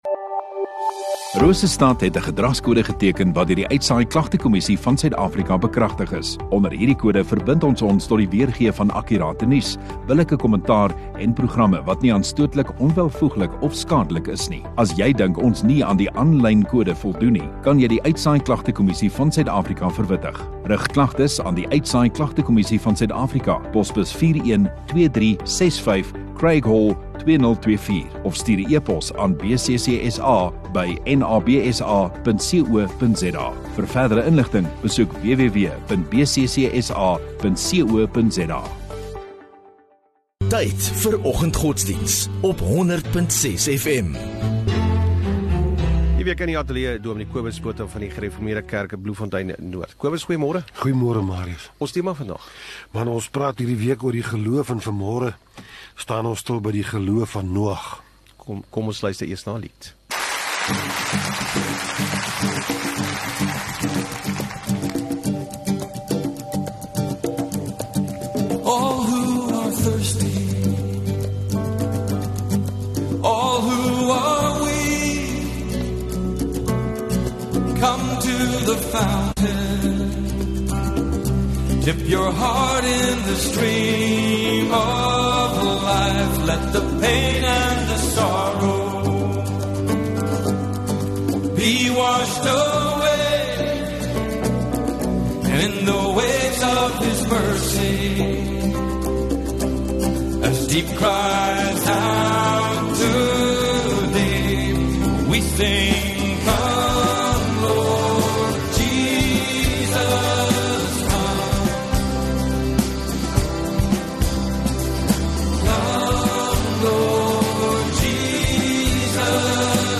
15 Aug Donderdag Oggenddiens